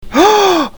SFX惊恐哦音效下载
SFX音效